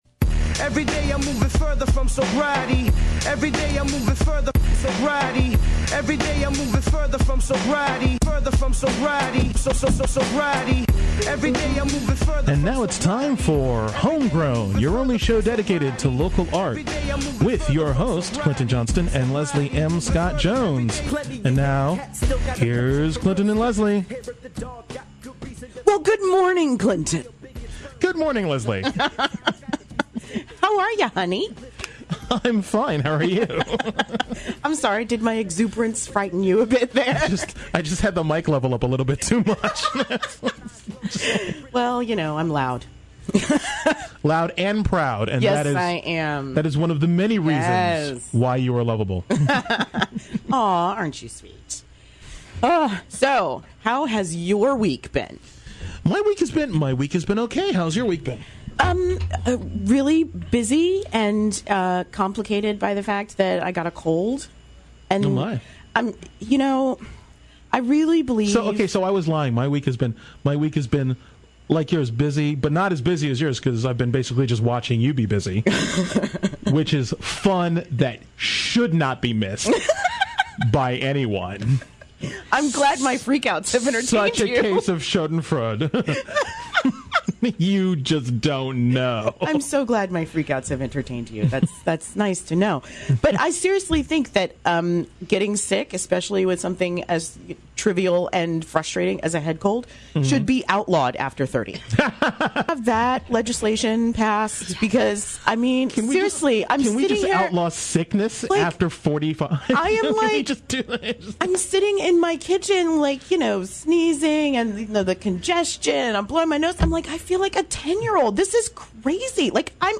(Oh, and they play a couple of songs too!)